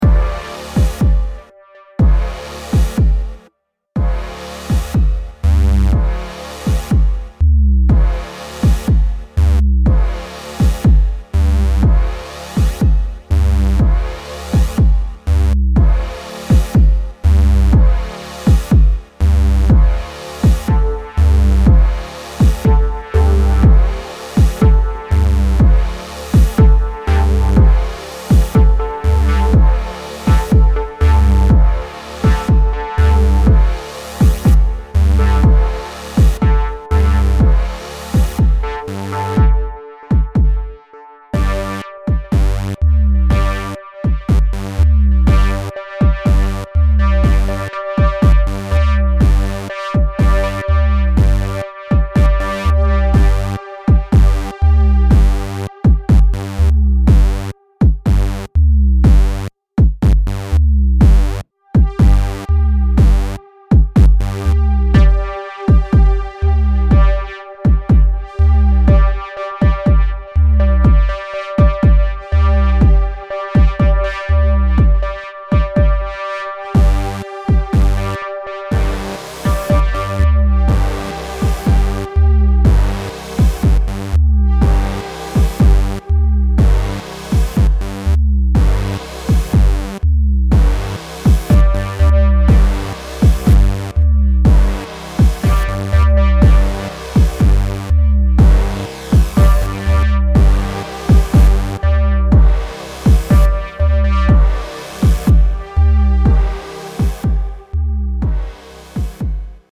Sensuel Lounge